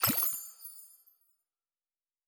Potion and Alchemy 10.wav